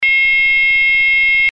ZUMBADOR - 4 SONIDOS
Zumbador Electrónico de 4 Sonidos Seleccionables
Amplio espectro de señal (530 a 2.700 Hz)
105dB